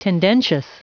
Prononciation du mot tendentious en anglais (fichier audio)
Prononciation du mot : tendentious